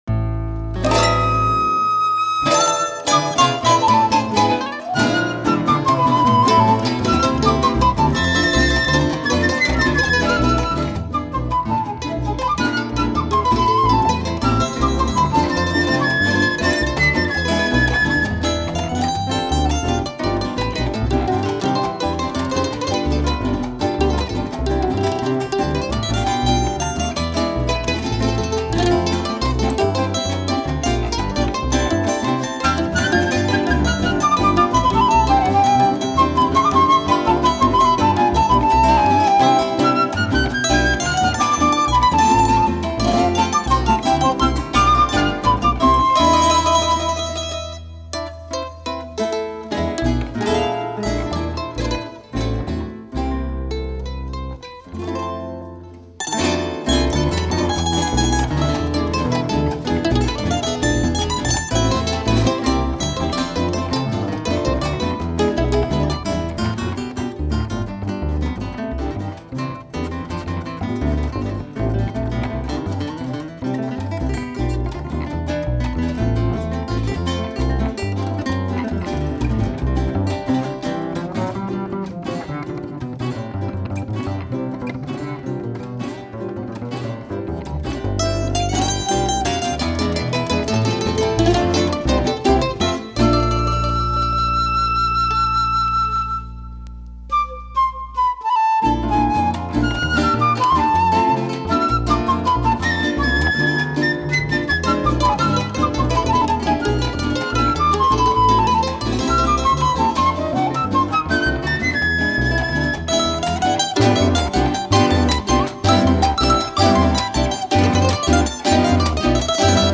Música latina
La música de América Latina